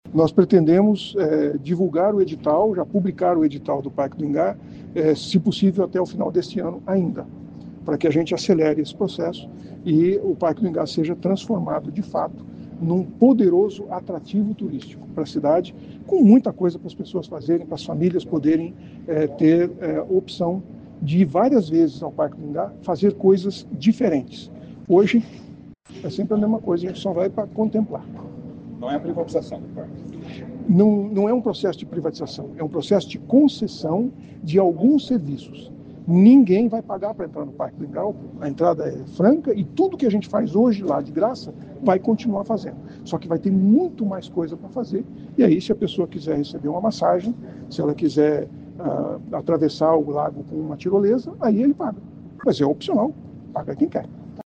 Em coletiva de imprensa, o prefeito Silvio Barros disse que até o final do ano, deve ser lançado um edital para a concessão de exploração de serviços turísticos no Parque do Ingá.